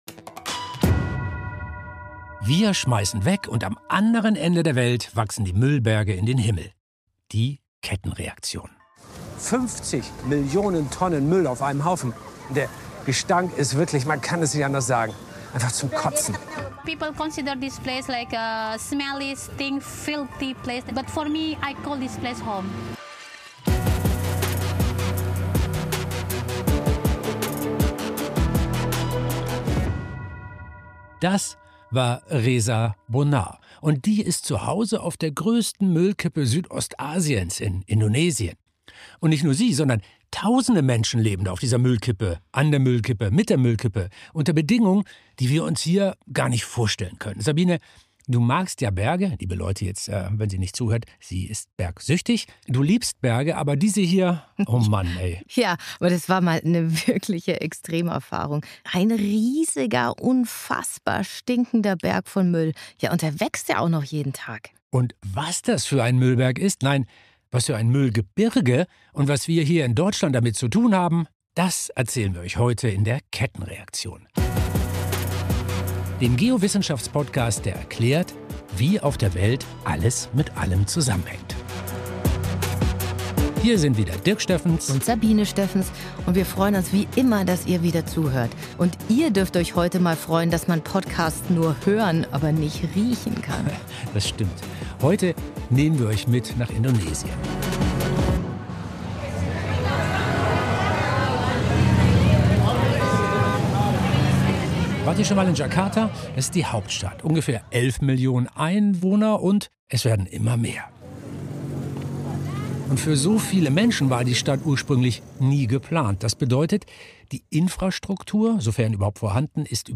Beschreibung vor 1 Jahr Eine Expedition nach Indonesien, auf die größte Müllkippe Südostasiens.